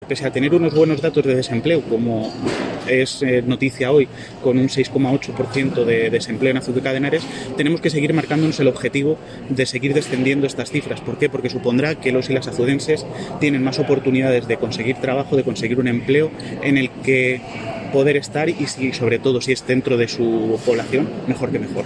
Declaraciones del alcalde sobre la tasa de desempleo en Azuqueca